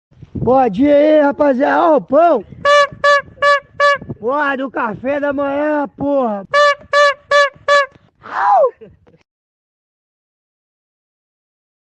olha o pao ai Meme Sound Effect